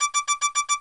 warning.ogg